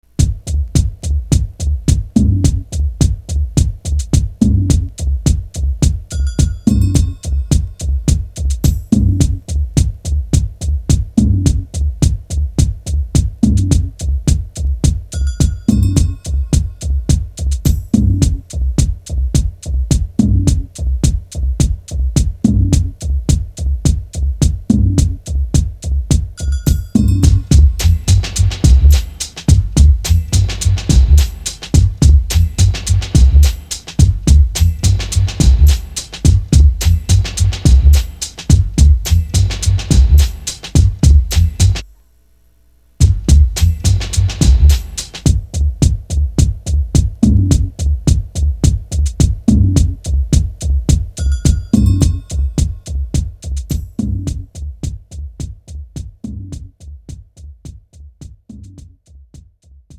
장르 pop 구분 Premium MR
Premium MR은 프로 무대, 웨딩, 이벤트에 최적화된 고급 반주입니다.